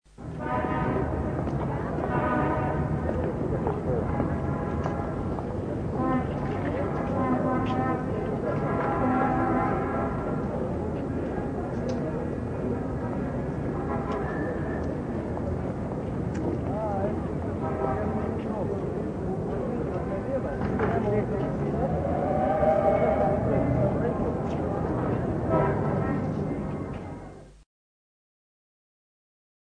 5. Кто-то справляет нужду в парковых зарослях